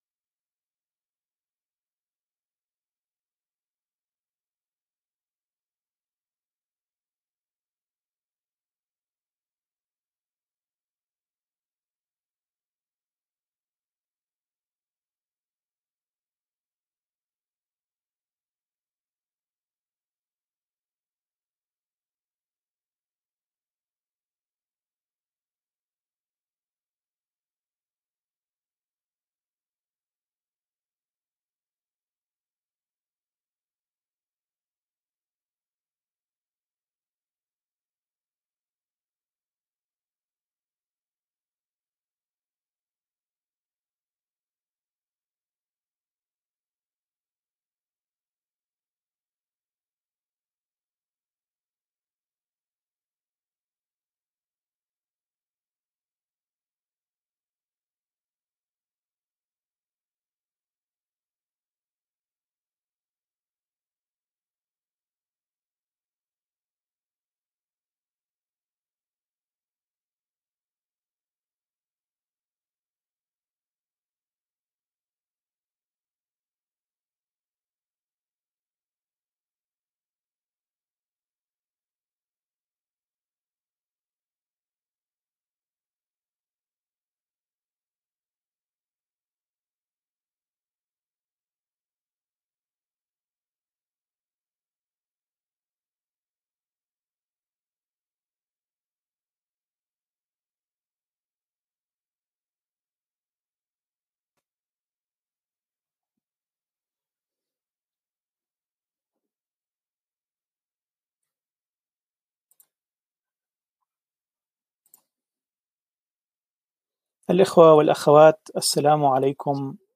الاخوة و الاخوات السلام عليكم و مرحباً بكم في اللقاء الأول على الإنترنت و البث المباشر لمنتدى الحوار الإسلامي …